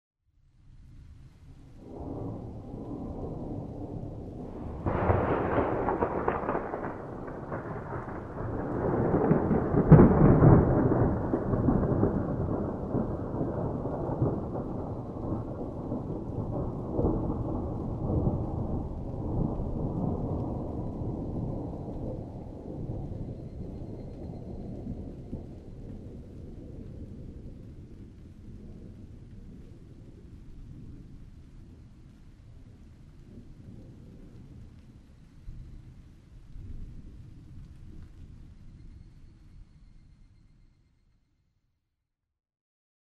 Thunder
日光市稲荷川中流　alt=730m
Mic: built-in Mic.